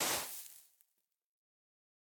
brush_sand_complete2.ogg